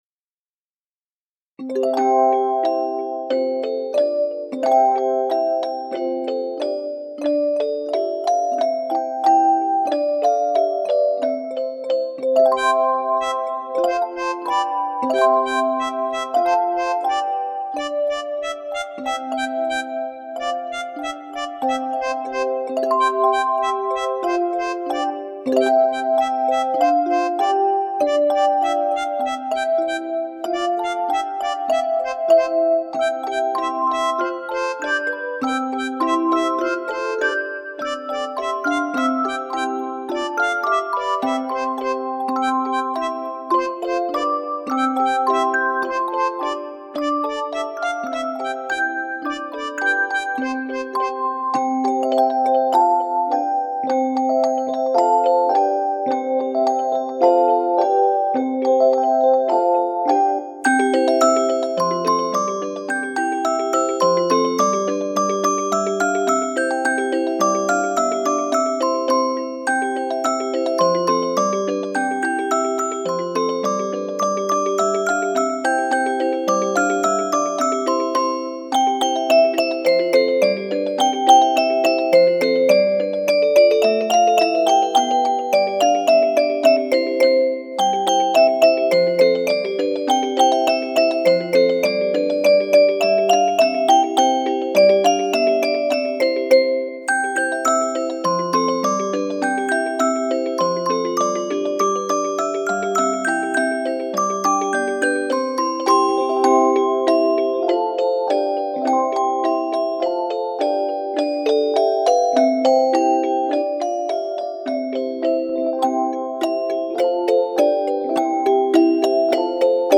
Русская народная песенка Тень-тень-потетень
detskie_pesni_-_ten-ten_poteten_poteshki.mp3